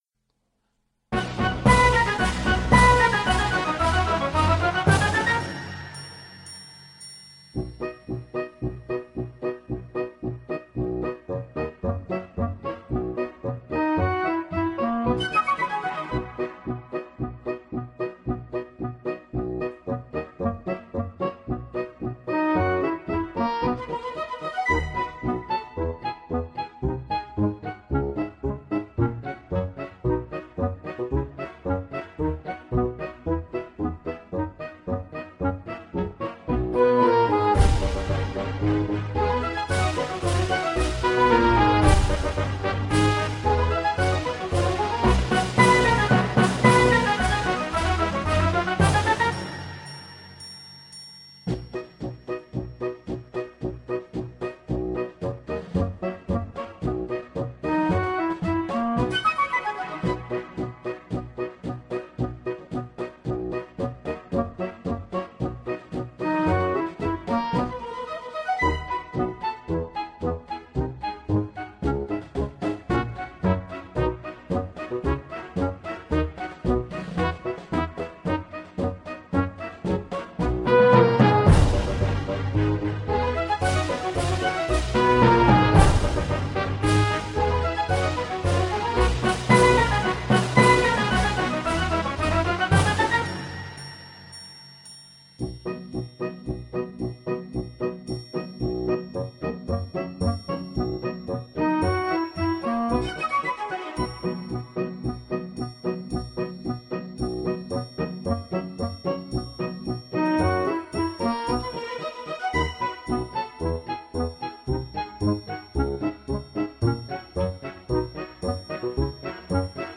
accompagnement de la chanson logo son
couplet-des-rois-acct-3ce-dessous-mp3.mp3